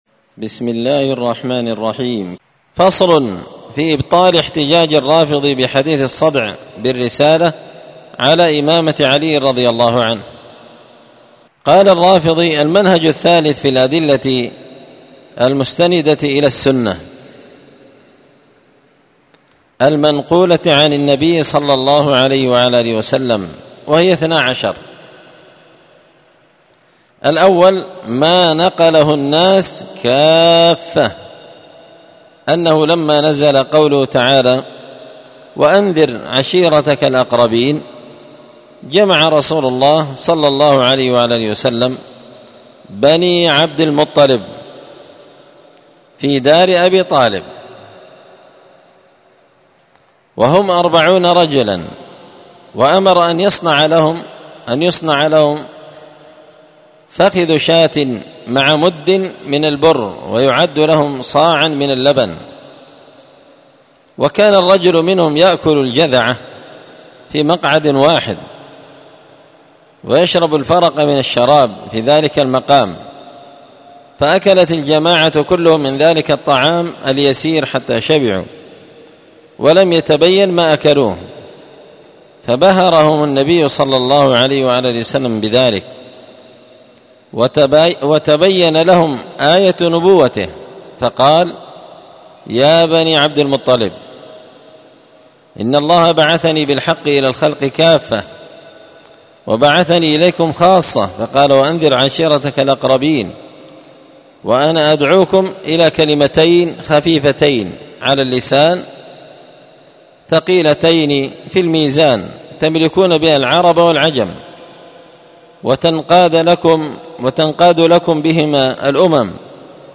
مختصر منهاج السنة النبوية لشيخ الإسلام ابن تيمية الحراني رحمة الله عليه ـ الدرس الحادي والتسعون بعد المائة (191) فصل في إبطال احتجاج الرافضي بحديث الصدع بالرسالة على إمامة علي
مسجد الفرقان قشن_المهرة_اليمن